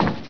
metal
metal4.ogg